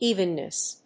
音節éven・ness 発音記号・読み方
/ˈivʌnnʌs(米国英語), ˈi:vʌnnʌs(英国英語)/